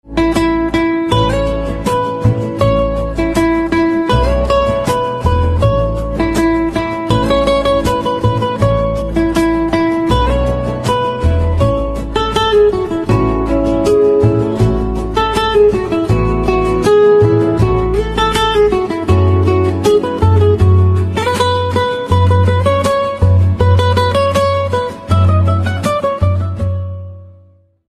3. Unique Guitar Instrumental Ringtone.
Guitar instrumental ringtones are stylish and modern.